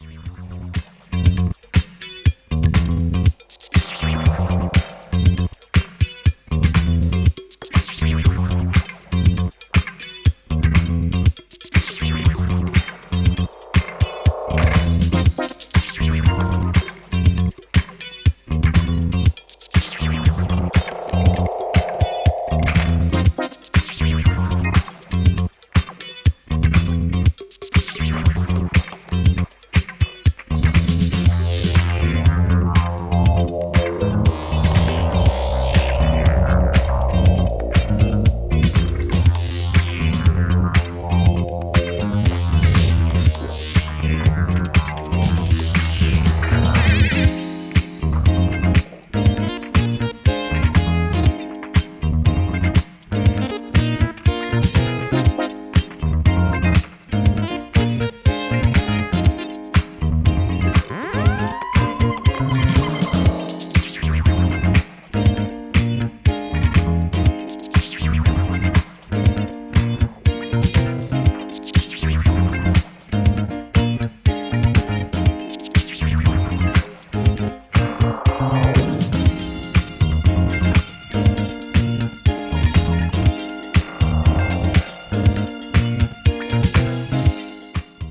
今回は、80'sテイストのエレクトリック・ファンク/ブギーを適度にルーズなテンションで展開。